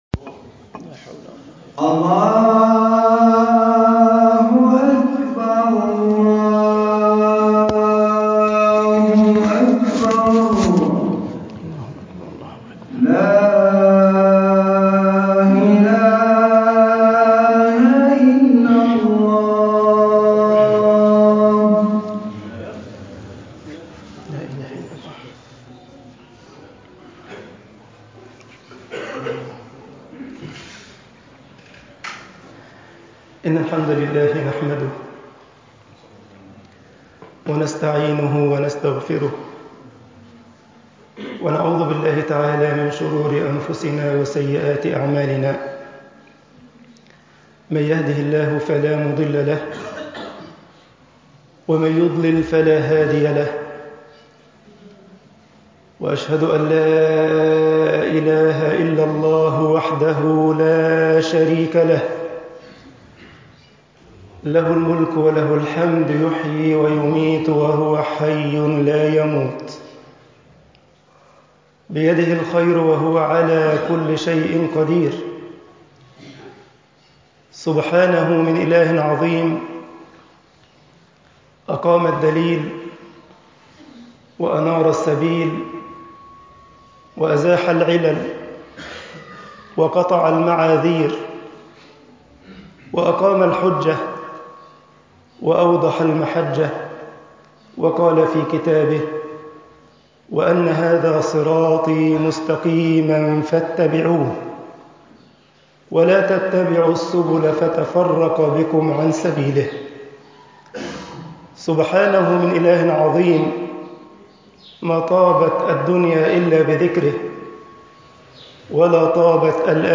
خطب الجمعة
khutab aljumaa misr 8_muhasabat annafs dalil aliman.mp3